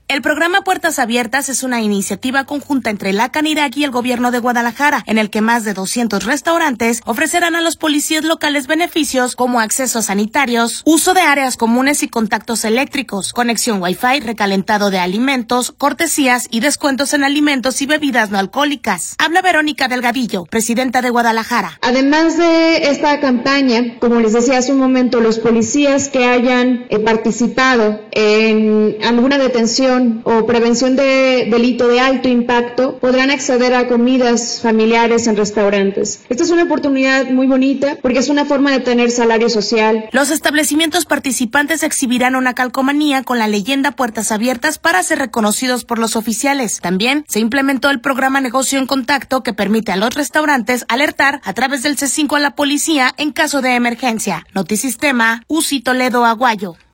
Habla Verónica Delgadillo, presidenta de Guadalajara.